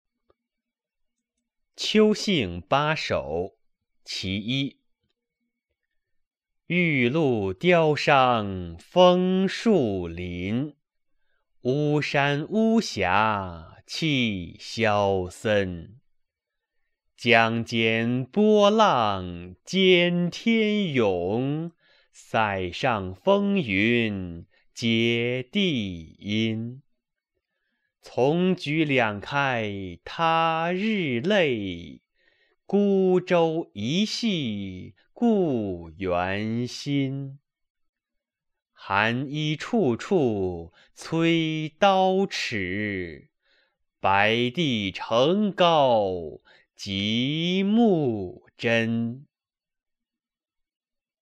《秋兴八首（其一）》原文与译文（含赏析、朗读）　/ 杜甫